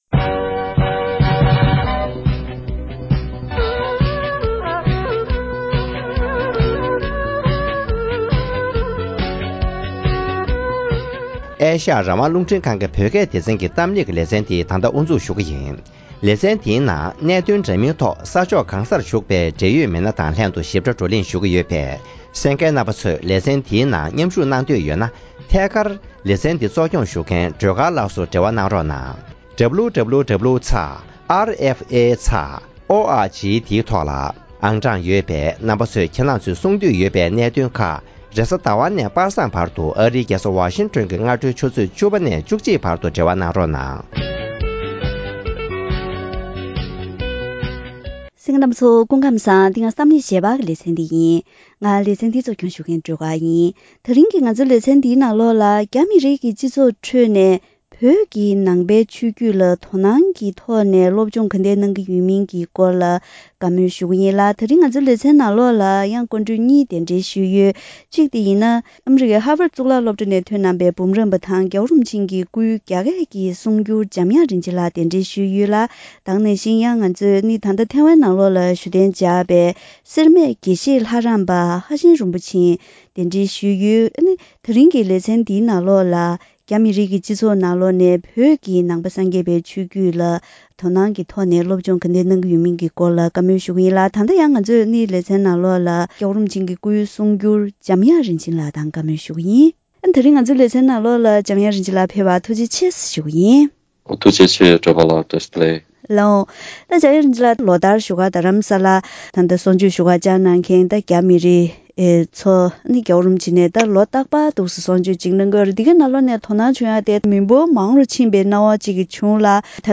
རྒྱ་མི་རིགས་ཀྱི་སྤྱི་ཚོགས་ཁྲོད་ནས་བོད་ཀྱི་ནང་ཆོས་ལ་དོ་སྣང་དང་སློབ་གཉེར་གནང་མཁན་མང་དུ་འགྲོ་བཞིན་ཡོད་པའི་ཐད་གླེང་མོལ།
ཐེངས་འདིའི་གཏམ་གླེང་ཞལ་པར་ལེ་ཚན་ནང་།